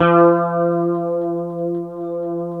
F#3 HSTRT MF.wav